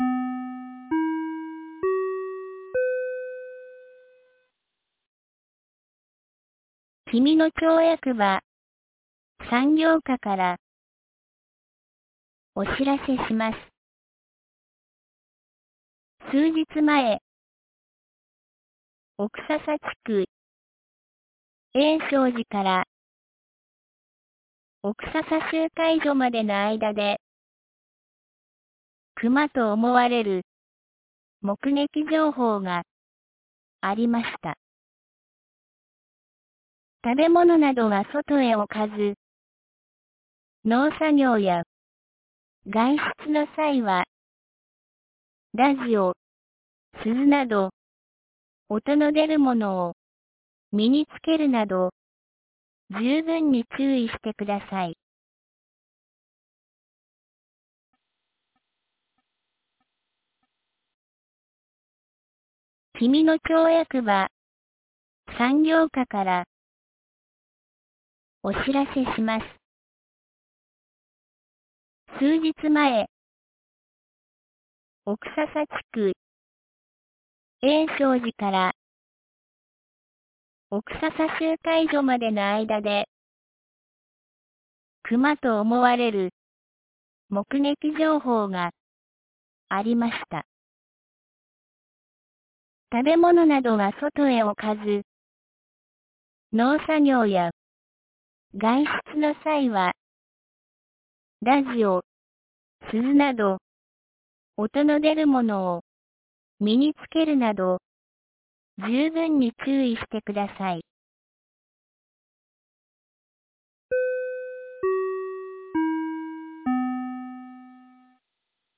2022年05月22日 17時07分に、紀美野町より小川地区へ放送がありました。
放送音声